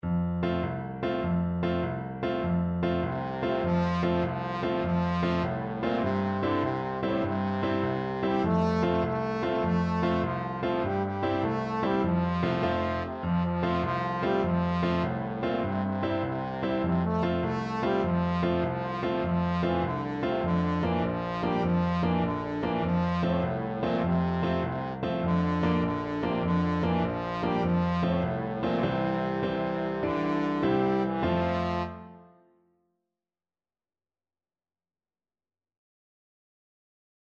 Allegro .=c.100 (View more music marked Allegro)
6/8 (View more 6/8 Music)